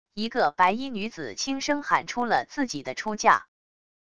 一个白衣女子轻声喊出了自己的出价wav音频